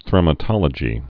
(thrĕmə-tŏlə-jē)